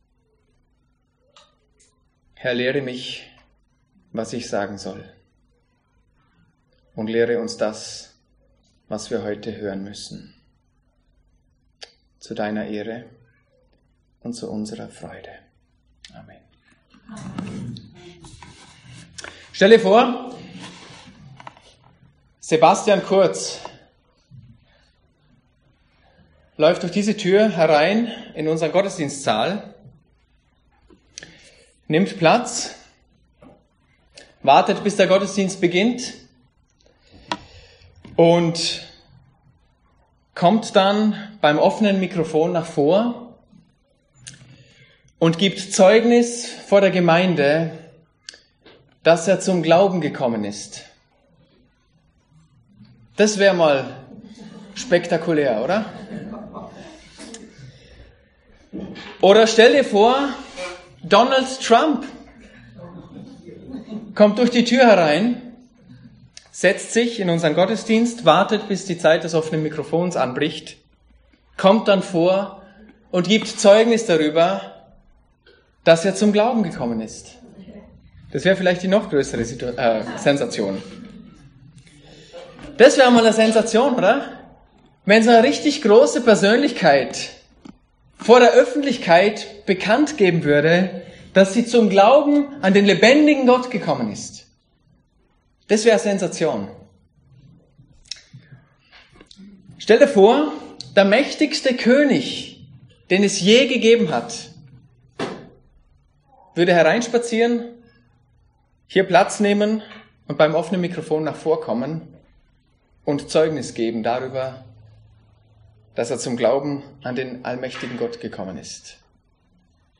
Die Predigt über Daniel 4 ist der 4. Teil einer Predigtreihe in der Christlichen Gemeinde Dornbirn durch das Buch Daniel – und über das Leben in einer Welt, die Kopf steht.